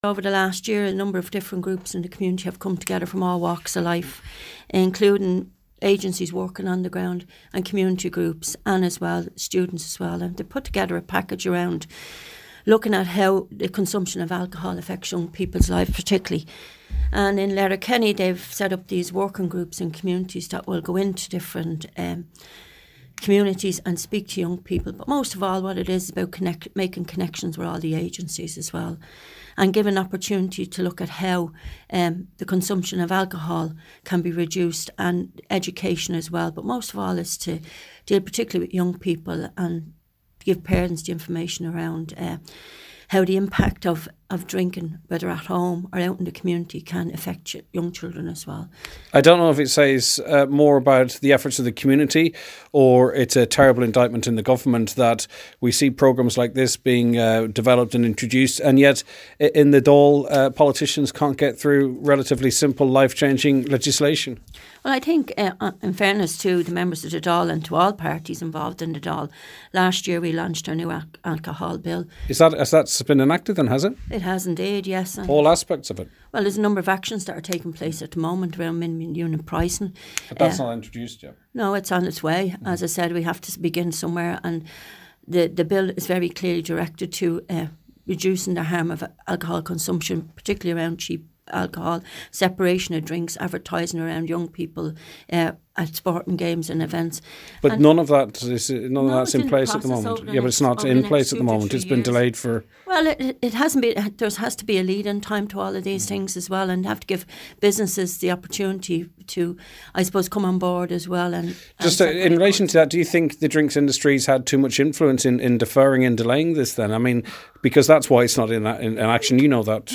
The special guest at the launch was Junior Minister Katherine Byrne.
01KatherineByrne.mp3